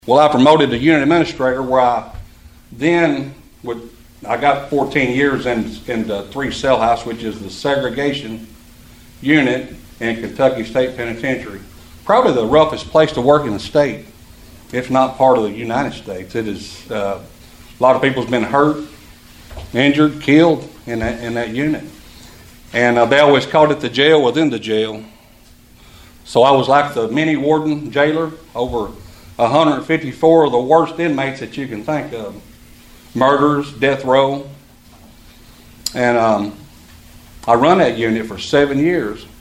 Trigg County Republican Jailer candidates touted experience, community service, and training during the recent republican party meet the candidates event in Cadiz.